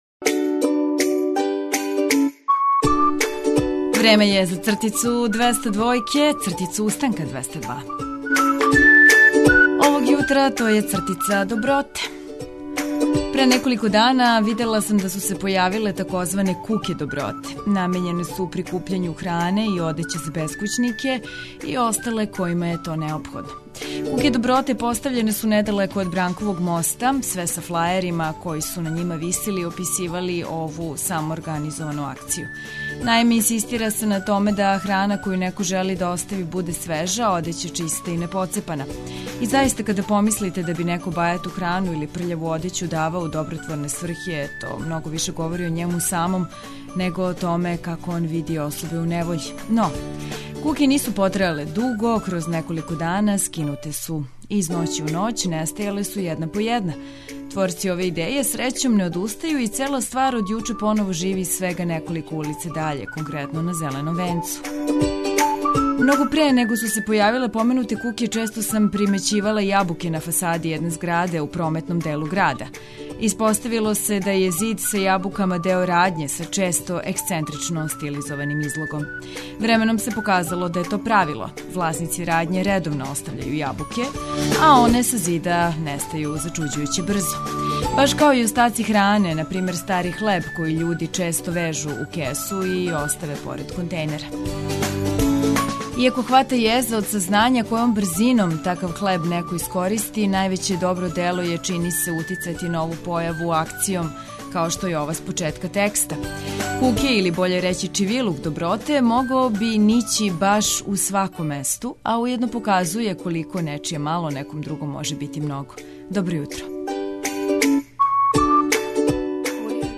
Доносимо низ корисних информација за почетак дана. Трудићемо се и да вас насмејемо, упутимо на различите актуелности али и размрдамо добром музиком.